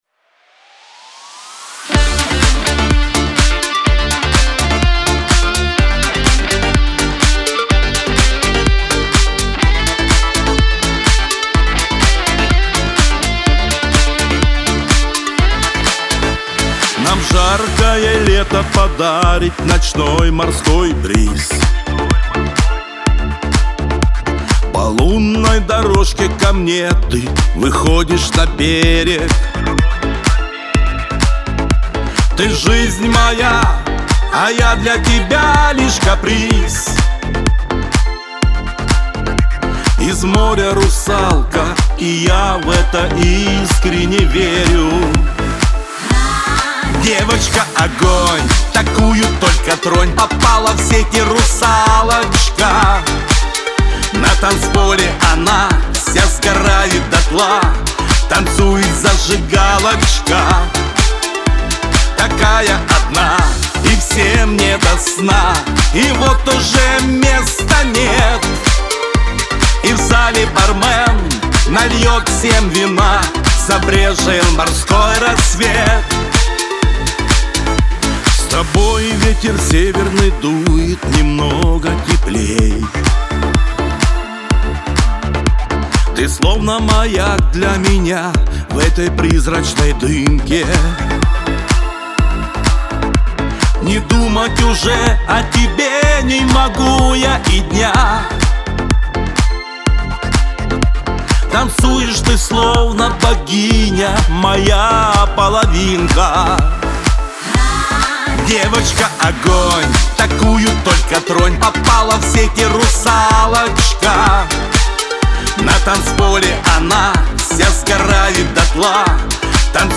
Шансон , Лирика